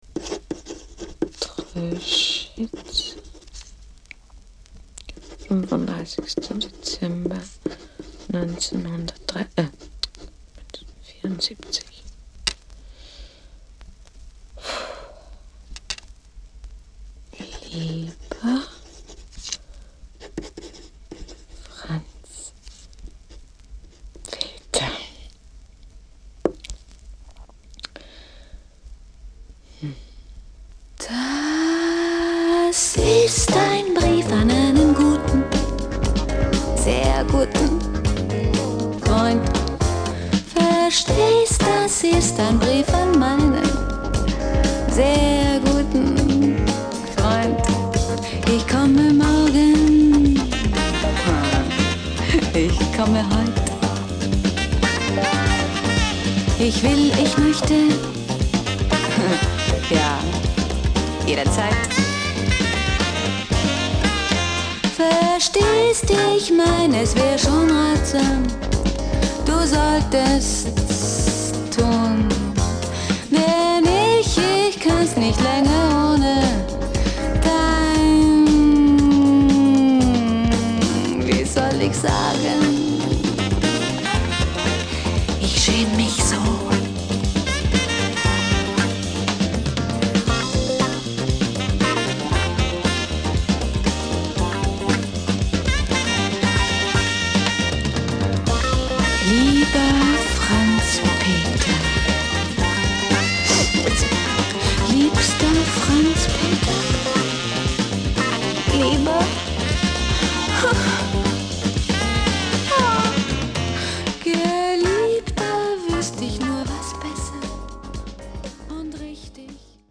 聴き手を夢見心地の気分に誘う逸品。